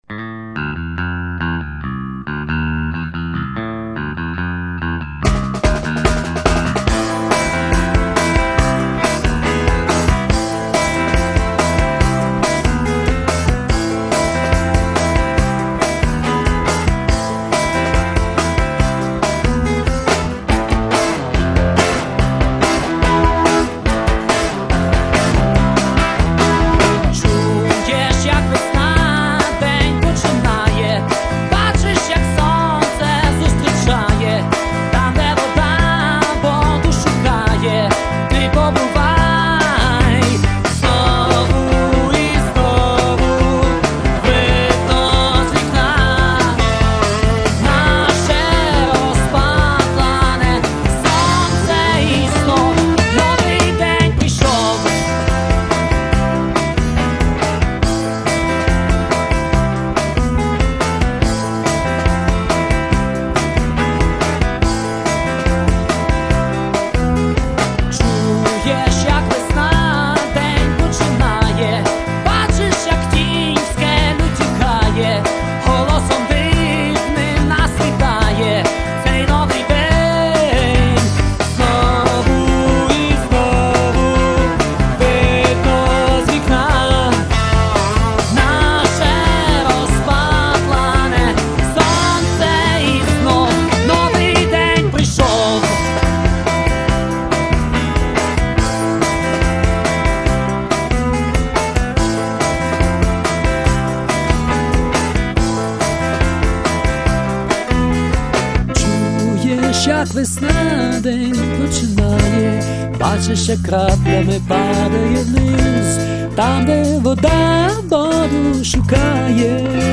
Рубрика: Рок